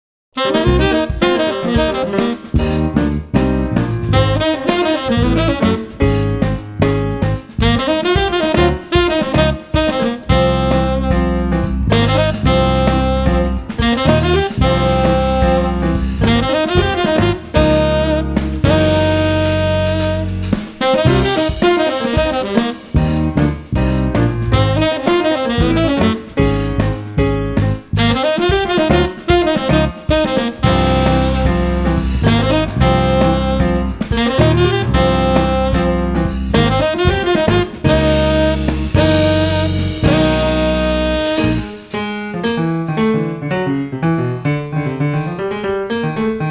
saxes
bass
drums & percussion
pianos and composer